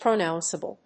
音節pro・nounce・a・ble 発音記号・読み方
/prənάʊnsəbl(米国英語)/
pronounceable.mp3